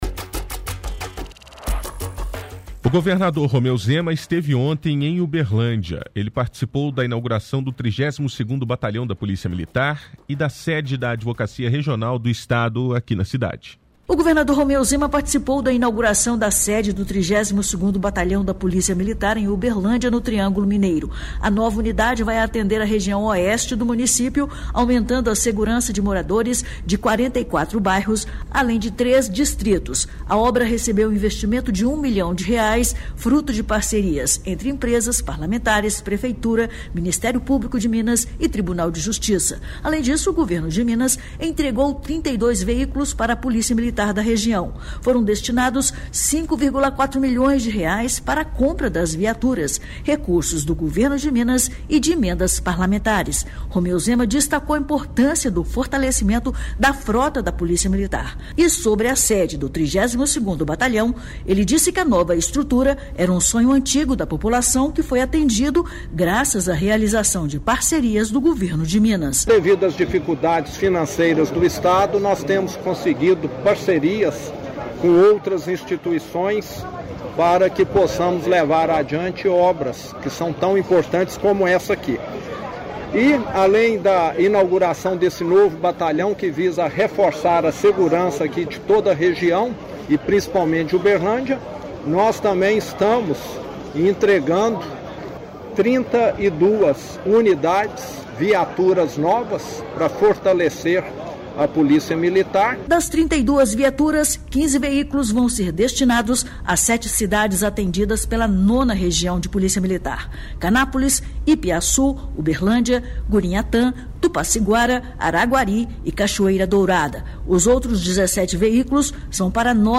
– Entrevista com governador.